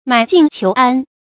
买静求安 mǎi jìng qiú ān
买静求安发音